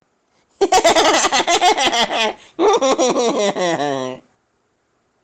Risada Debochada Brincalhão
Homem solta uma risadinha brincalhona e debochada.
risada-debochada.mp3